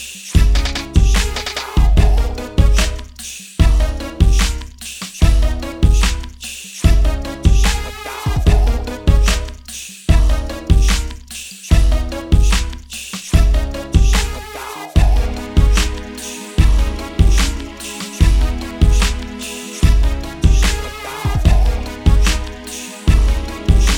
no Backing Vocals R'n'B / Hip Hop 4:49 Buy £1.50